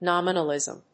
音節nóm・i・nal・ìsm 発音記号・読み方
/‐nəlìzm(米国英語)/